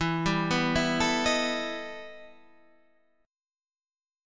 E Minor Major 11th